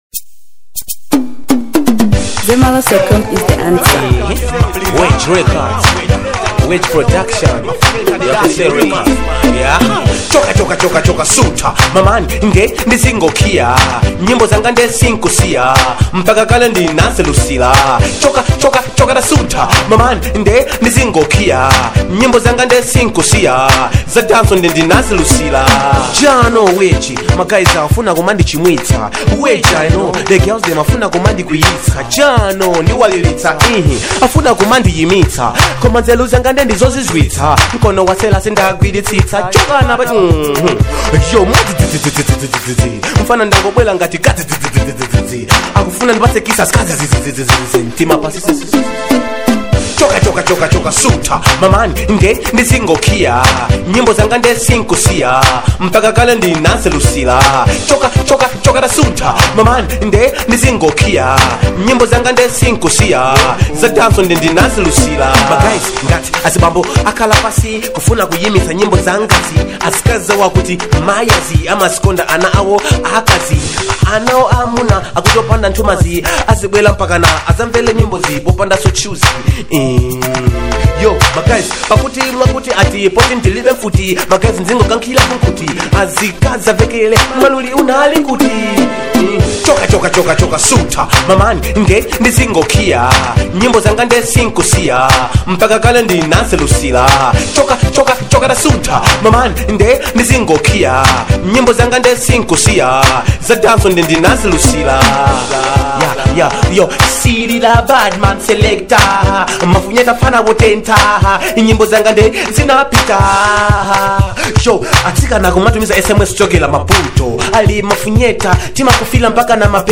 Dancehall • 2025-09-15